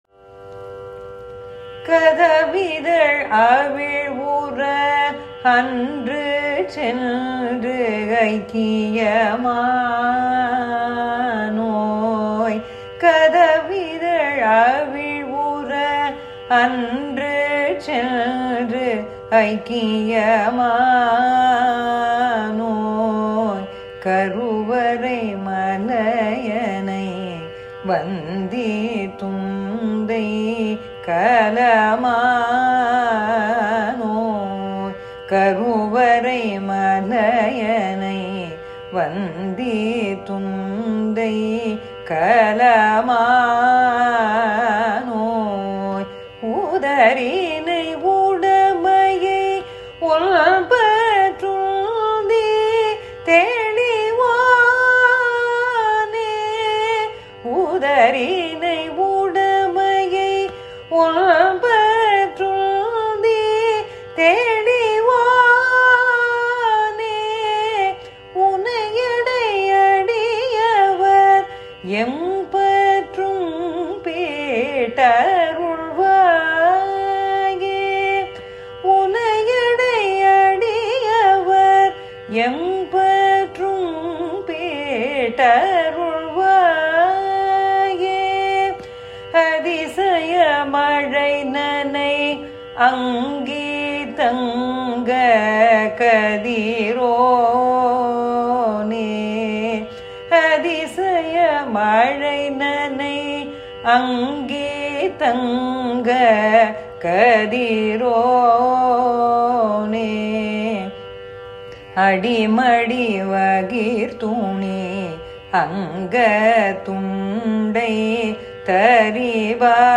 பகவான் அருணை நுழை திருப்புகழ் வாய்ப்பாடு தனதன தனதன தந்தத் தந்தத் தனதான ......தனதன தனதன தந்தத் தந்தத் தனதானா (பரிமள களப என்ற திருப்புகழ் மெட்டில்)